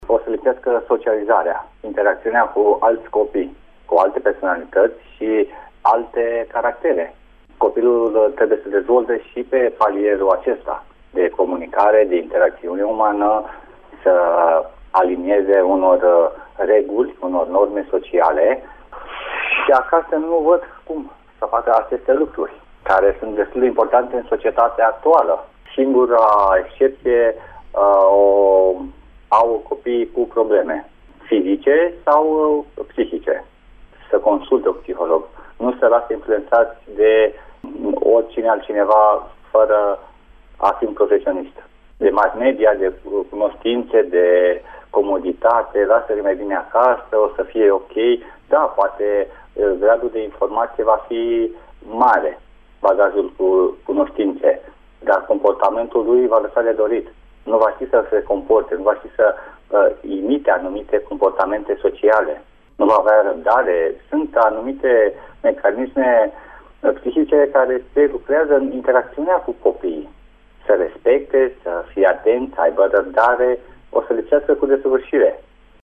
psiholog-despre-invatatul-acasa-1.mp3